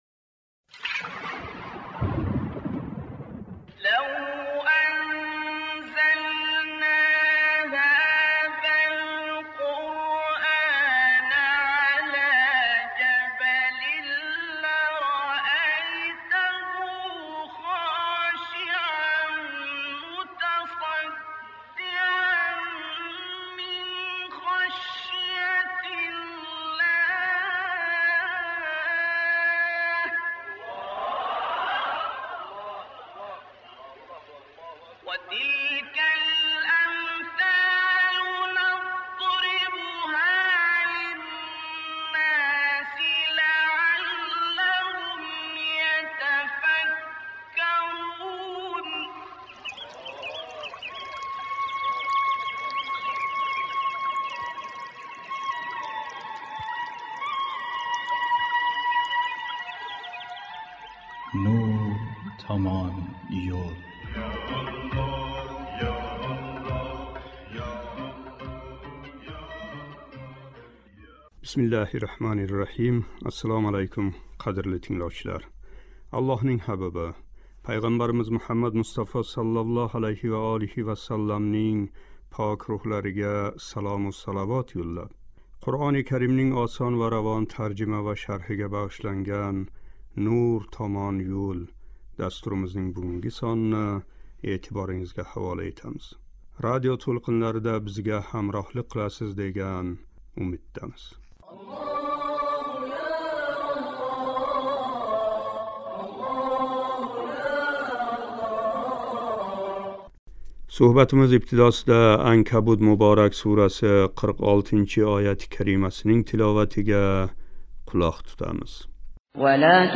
"Анкабут" муборак сурасининг 46-49- ояти карималари шарҳи. Суҳбатимиз ибтидосида «Анкабут" муборак сураси 46-ояти каримасининг тиловатига қулоқ тутамиз.